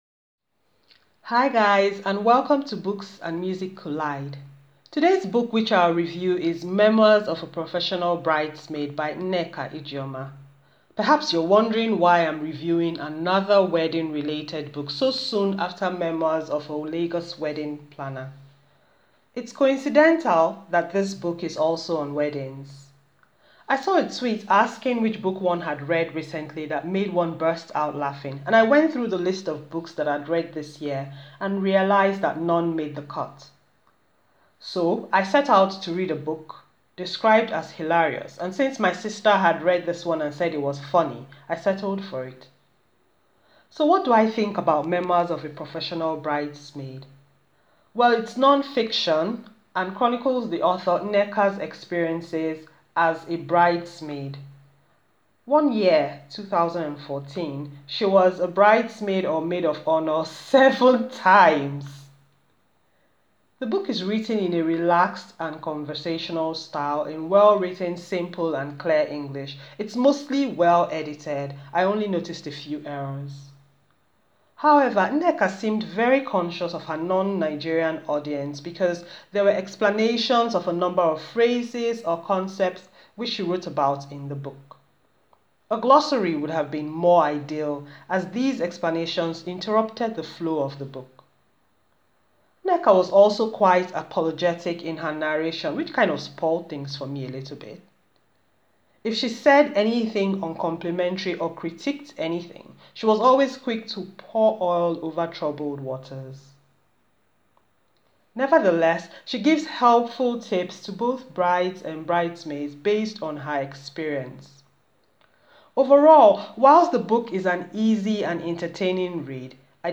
An audio book review of Memoirs of a Professional Bridesmaid by Nneka Ijeoma. A nonfiction account by the author about her experiences as a bridesmaid.